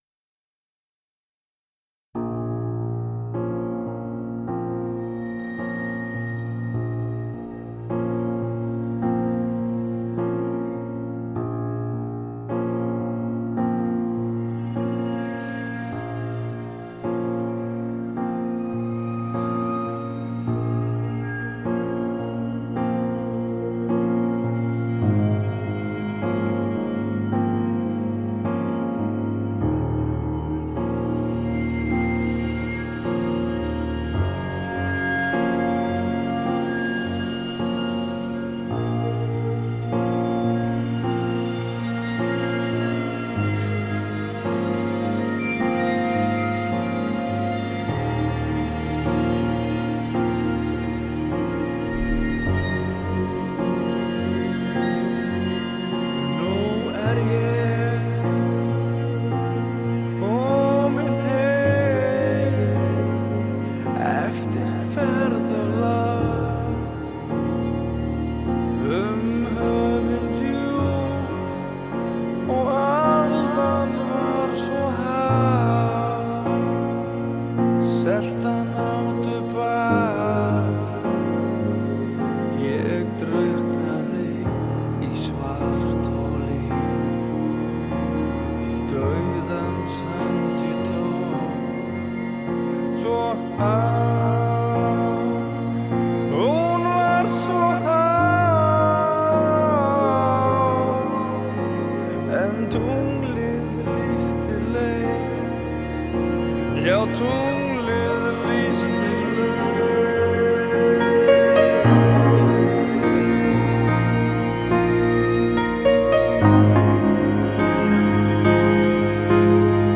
Post-Black Metal